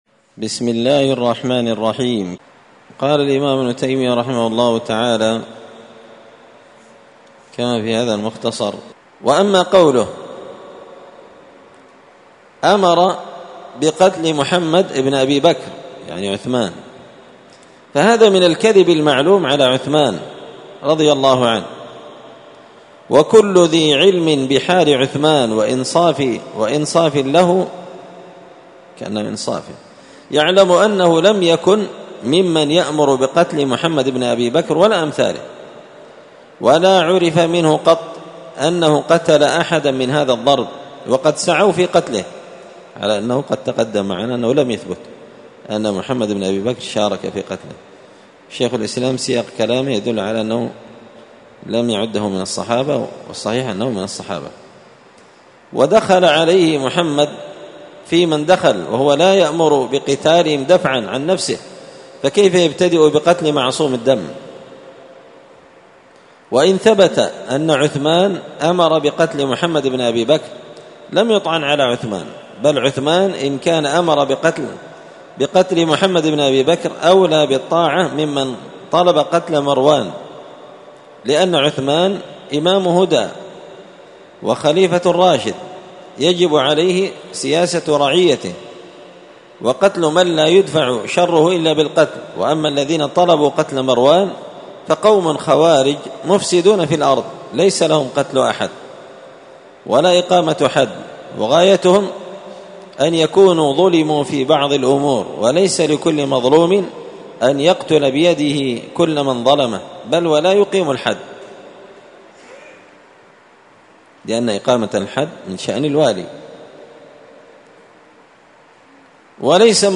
الخميس 25 ذو الحجة 1444 هــــ | الدروس، دروس الردود، مختصر منهاج السنة النبوية لشيخ الإسلام ابن تيمية | شارك بتعليقك | 9 المشاهدات
مسجد الفرقان قشن_المهرة_اليمن